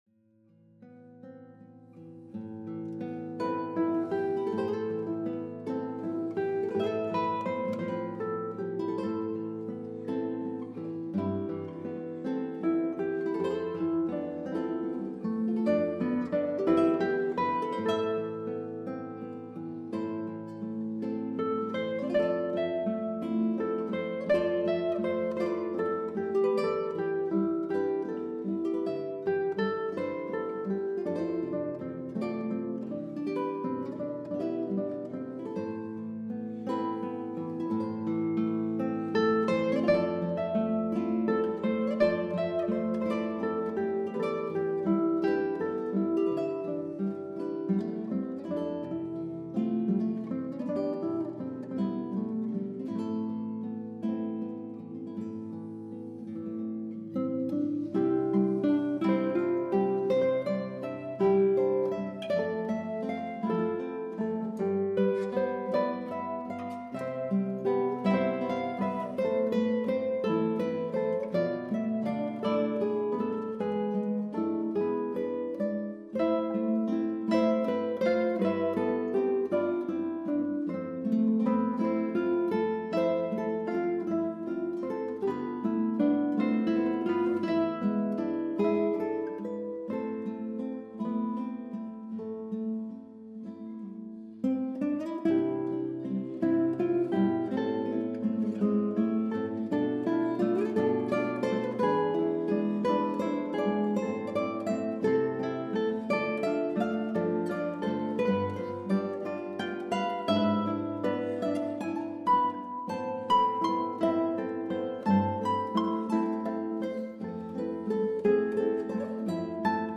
On entendra aussi des échos d’airs celtiques ou de blues.